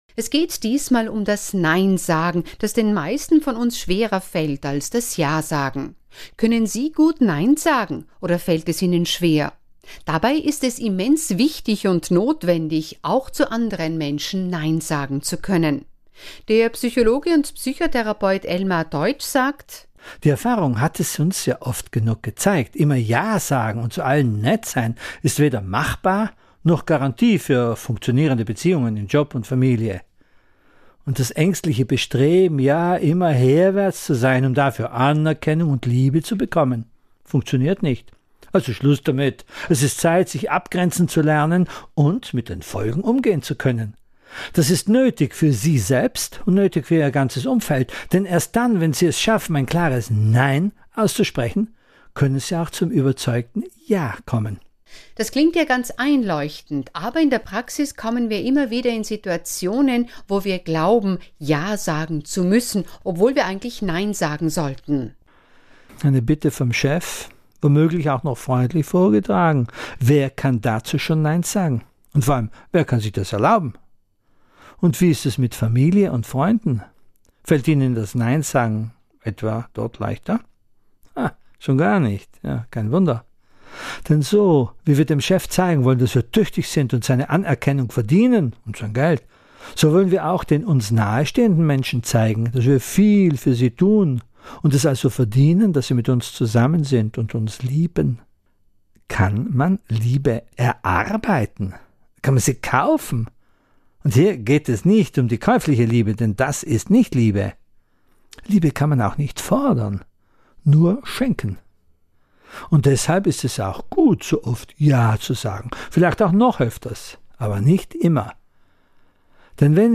Die Radiosendung zum Nachhören, jetzt kostenlos auf dieser Seite.